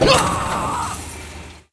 machine_die2.wav